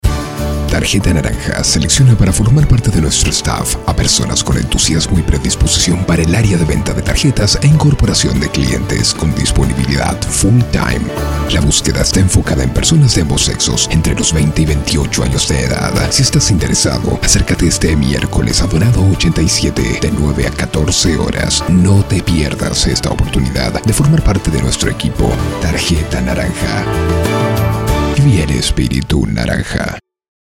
spanisch SĂŒdamerika
chilenisch
Sprechprobe: Sonstiges (Muttersprache):
I have 4 different styles of voice and I can come in neutral tones and as agile and high tones.